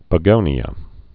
(pə-gōnē-ə, -gōnyə)